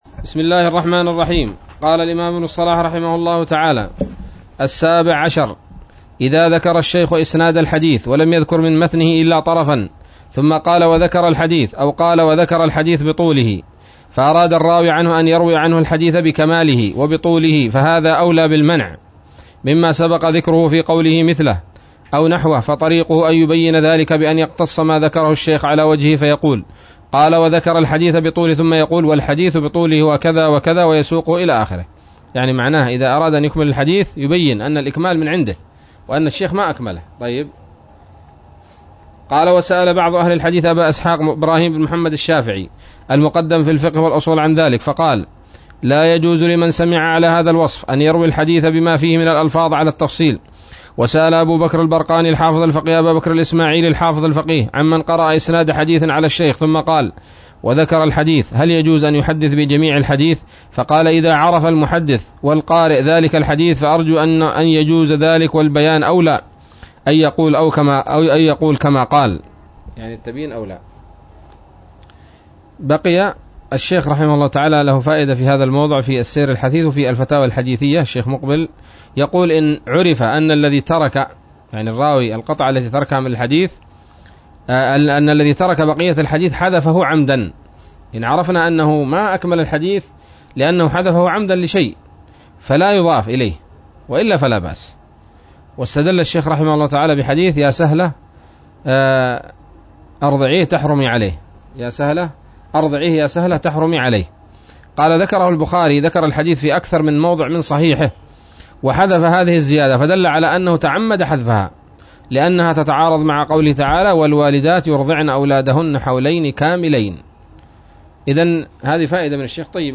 الدرس الثالث والثمانون من مقدمة ابن الصلاح رحمه الله تعالى